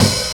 43 OP HAT.wav